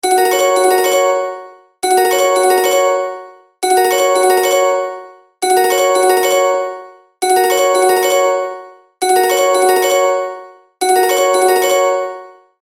دانلود آهنگ زنگ 2 از افکت صوتی اشیاء
دانلود صدای زنگ 2 از ساعد نیوز با لینک مستقیم و کیفیت بالا
جلوه های صوتی